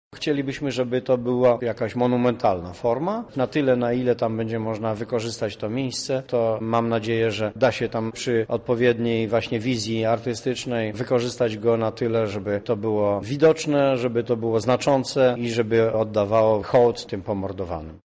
• Ofiary komunizmu zasługują na pamięć i szacunek – mówi radny Rady Miasta Stanisław Brzozowski.